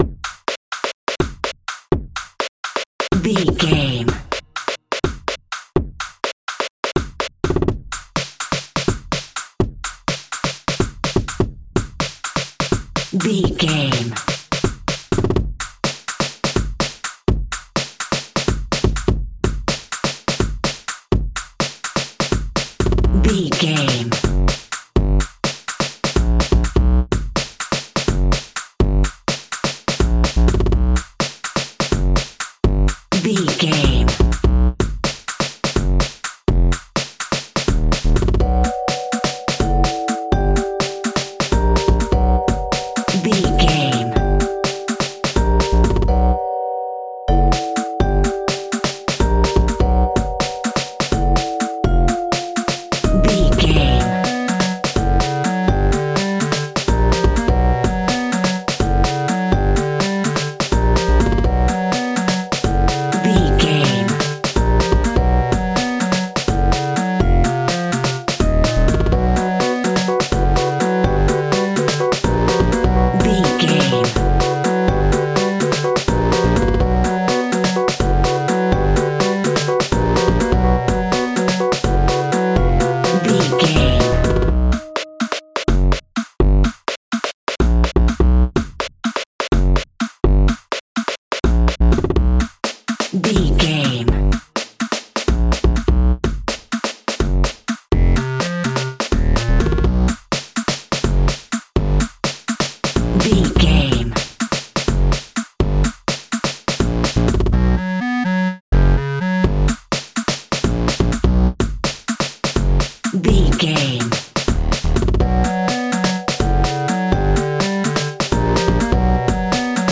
Aeolian/Minor
funky
groovy
uplifting
futuristic
driving
energetic
electric piano
drum machine
Drum and bass
break beat
electronic
sub bass
synth lead
synth bass